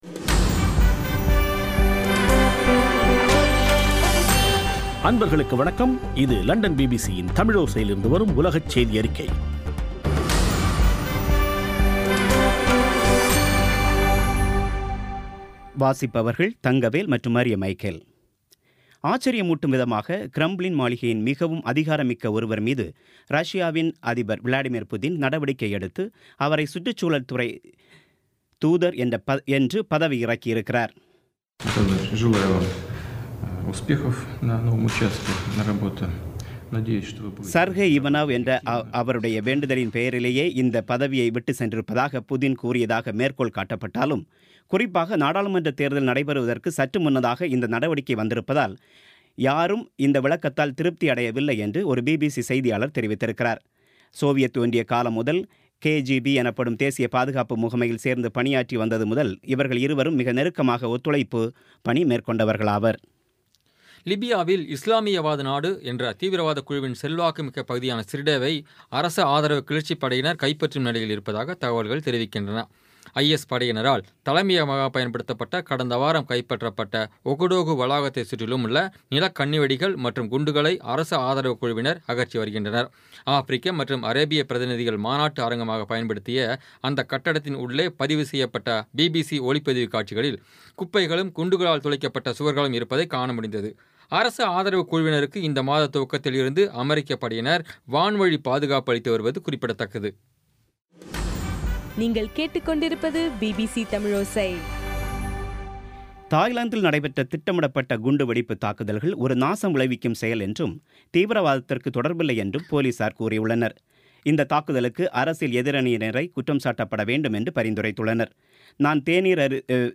இன்றைய (ஆகஸ்ட் 12ம் தேதி ) பிபிசி தமிழோசை செய்தியறிக்கை